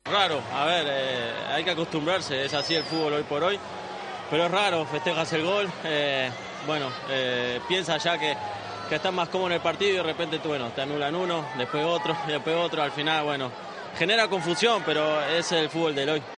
AUDIO: El jugador del Real Madrid volvió a marcar otro gol en el partido ante el Elche y habló en DAZN de los tres goles anulados a los blancos.